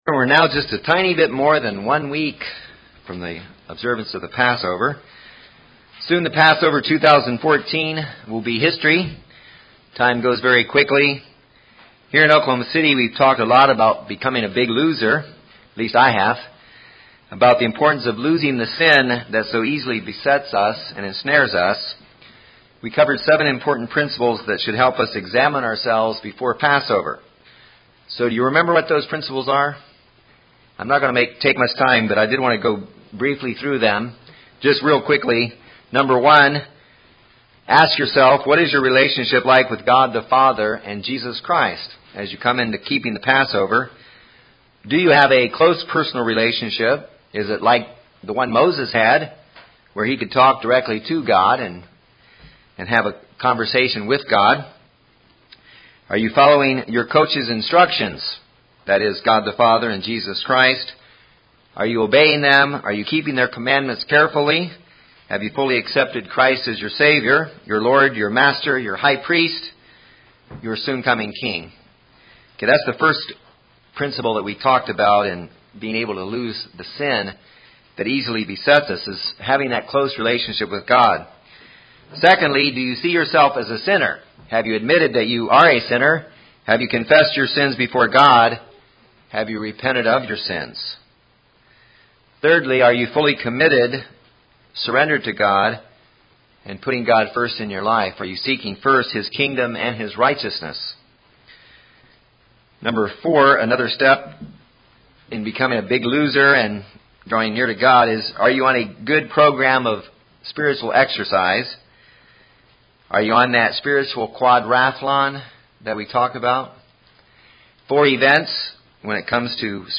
This sermon addresses how to examine oneself before the passover and how to prepare onself to appropriately keep the Passover.